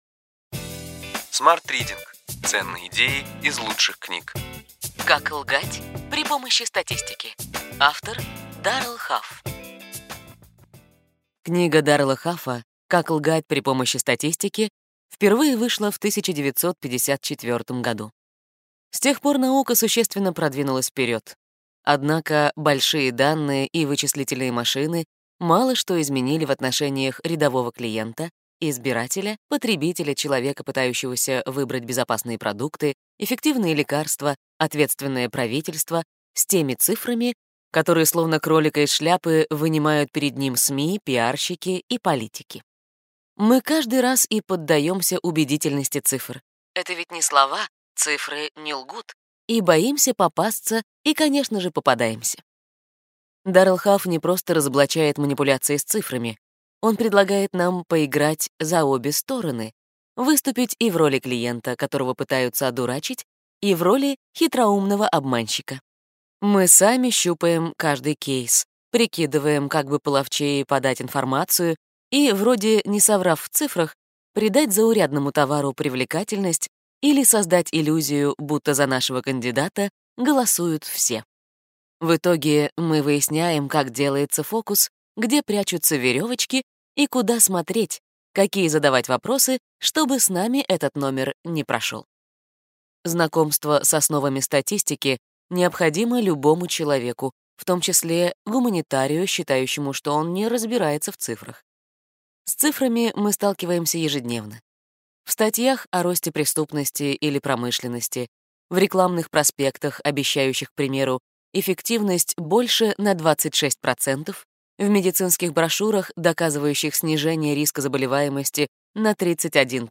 Аудиокнига Ключевые идеи книги: Как лгать при помощи статистики.